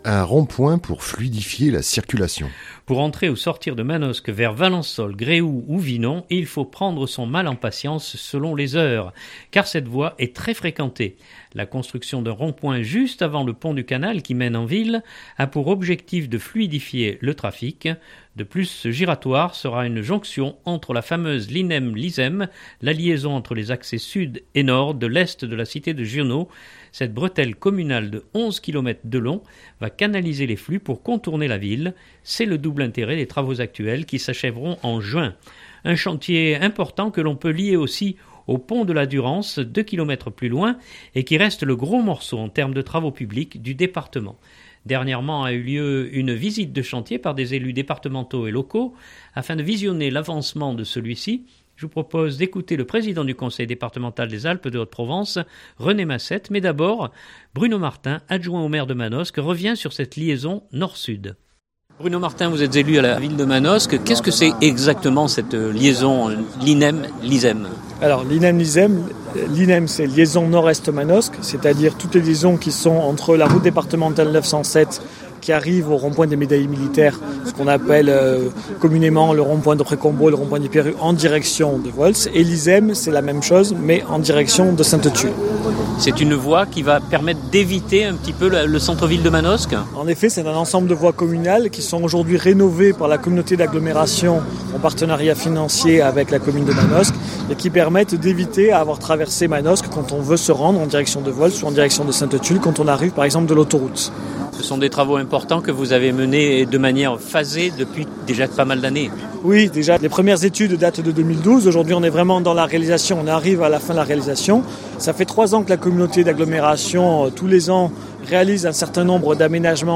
Dernièrement a eu lieu une visite de chantier par des élus départementaux et locaux afin de visionner l’avancement de celui-ci. Je vous propose d’écouter le président du Conseil départemental des Alpes de Haute-Provence René Massette mais d’abord Bruno Martin adjoint au maire de Manosque revient sur cette liaison nord-sud.